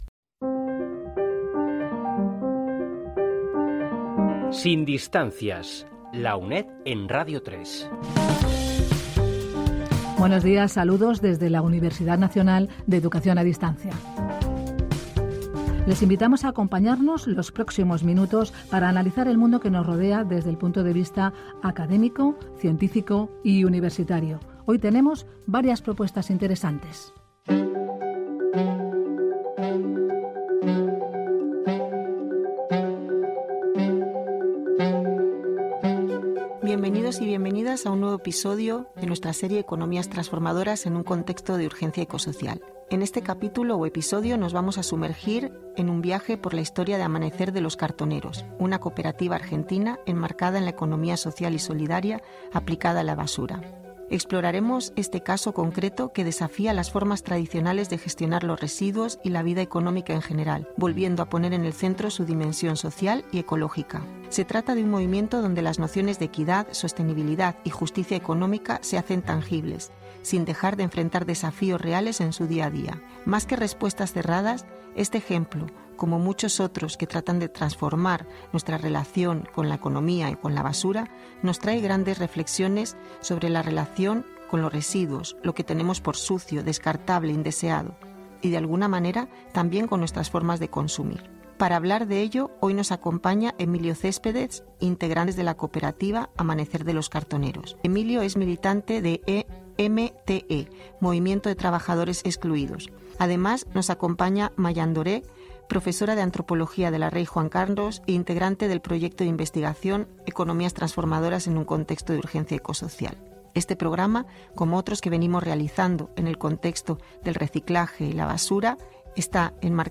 Careta del programa, presentació i tema sobre l'economia trasnsformadora en un context d'urgència ecosocial.
Divulgació
FM